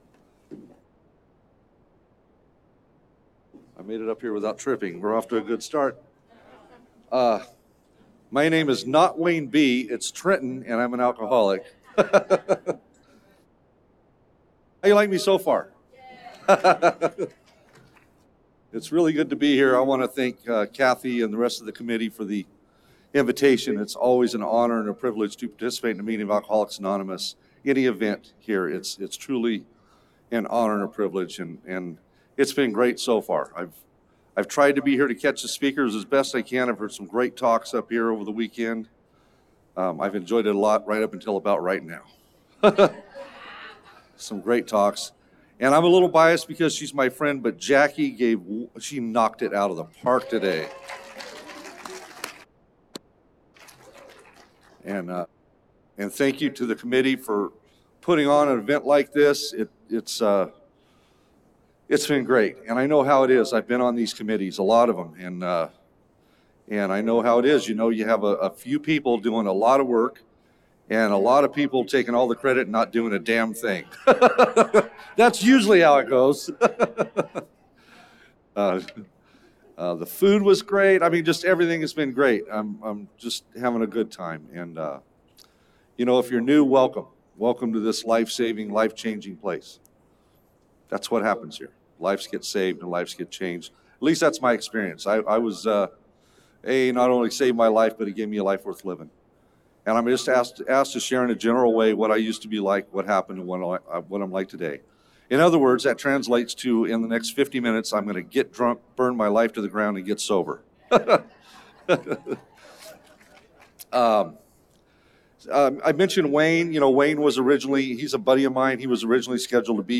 33rd Indian Wells Valley AA Roundup with Al-Anon and NA